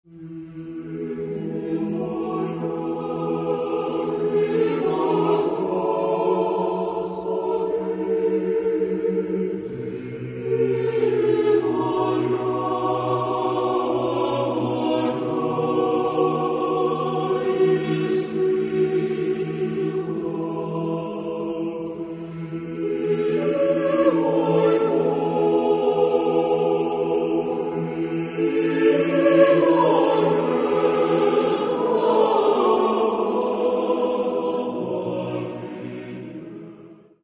- Хорові концерти: